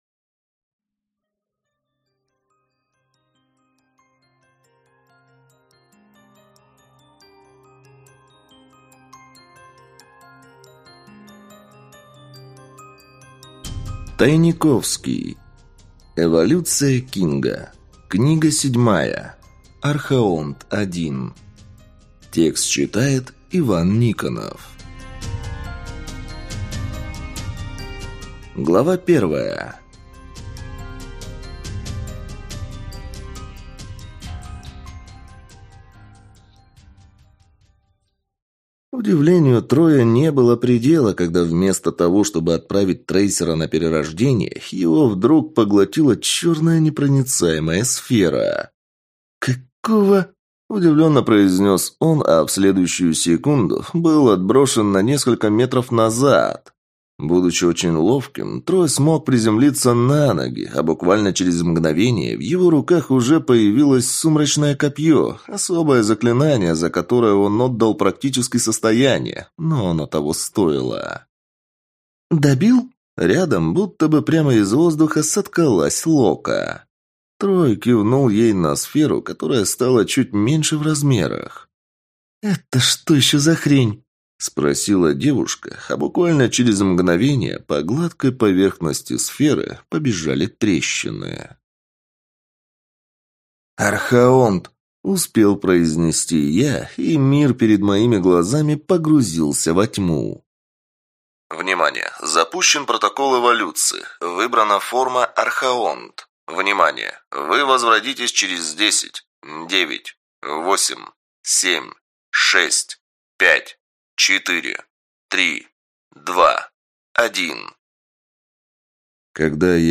Аудиокнига Архаонт(I) | Библиотека аудиокниг